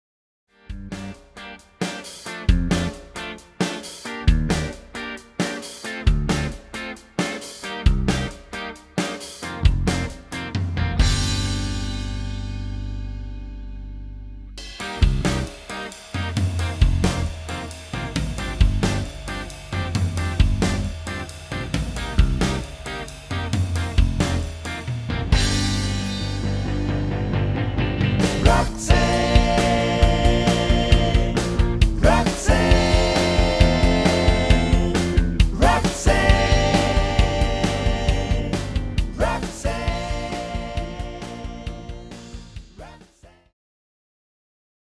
This is the Backing Track without Vocals